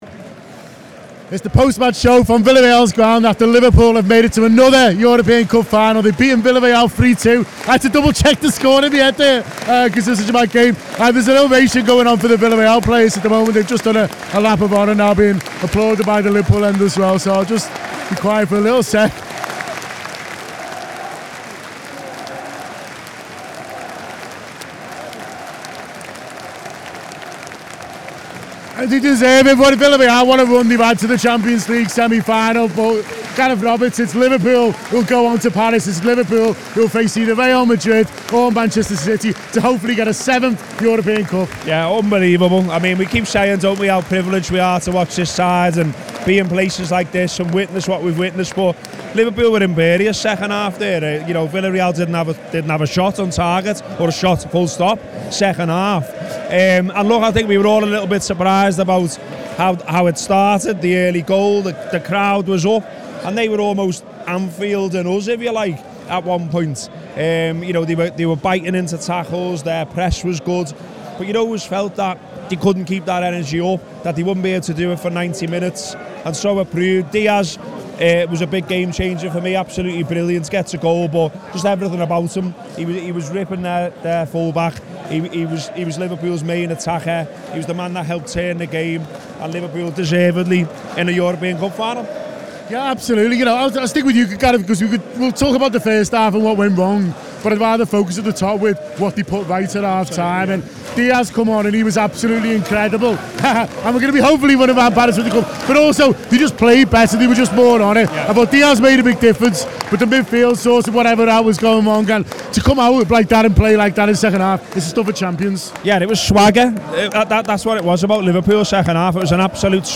Below is a clip from the show – subscribe for more Villarreal v Liverpool reaction…